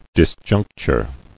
[dis·junc·ture]